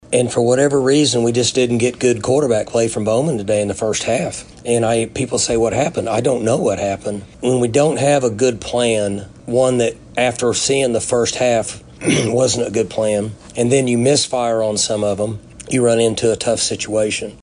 Mike Gundy talked about his team’s woes after.
Gundy Postgame 9-23.mp3